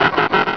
pokeemmo / sound / direct_sound_samples / cries / corphish.wav
corphish.wav